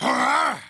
Wolf- Grunt 2 Sound Buttons
wolf-grunt-2.mp3